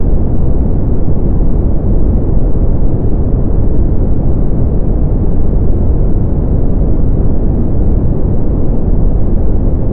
Brown Noise for Sleep — Free Deep Sleep Sounds
Fall asleep faster with deep, soothing low-frequency sound. No gaps, no interruptions.
brown_noise_deep.mp3